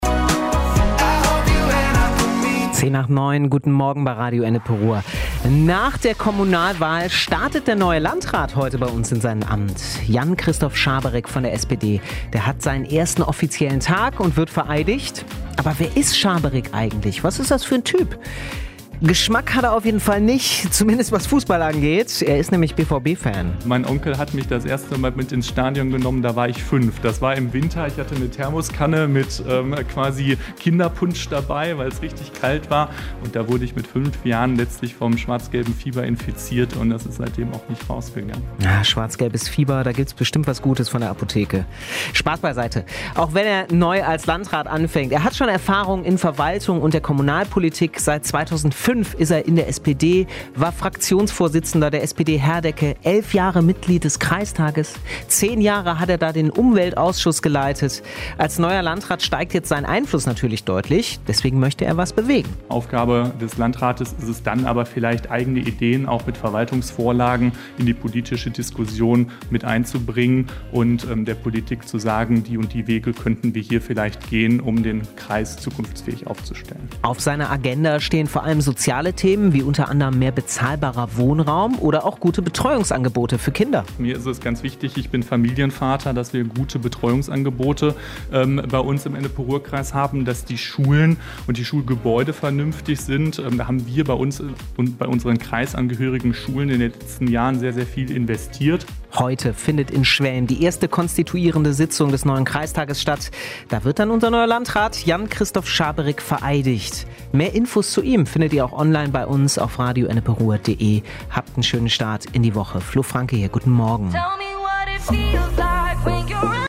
Heute tritt Jan-Christoph Schaberick sein Amt als neuer Landrat des Ennepe-Ruhr-Kreises an. Wie er tickt, was er sich für seine Amtszeit vornimmt und wie er auf die angespannte Finanzlage in den Städten und bei uns im Kreis schaut, findet ihr im großen Interview zu seinem Amtsantritt.